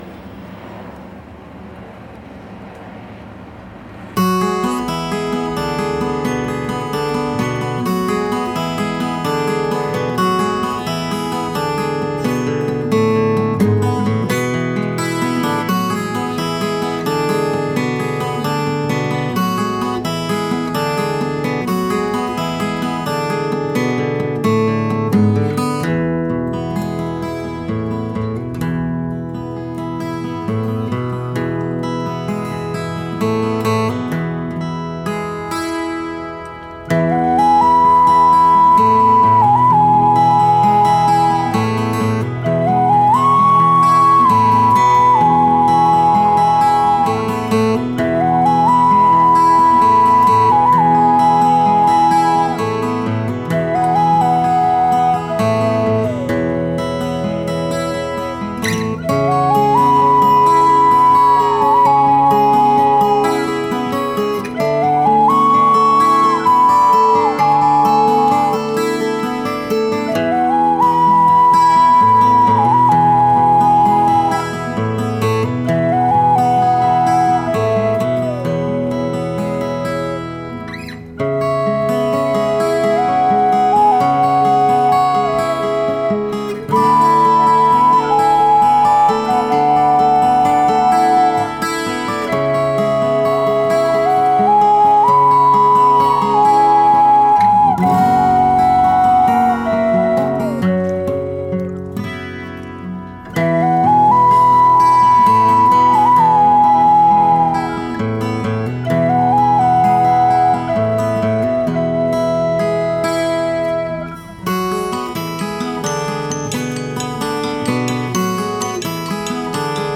チャランゴ、バンジョー、カリンバなどを駆使したフォルクローレ経由のSSW作品！